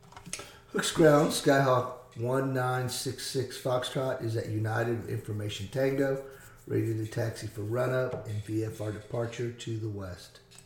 Aviation Radio Calls